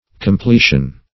Completion \Com*ple"tion\, n. [L. completio a filling, a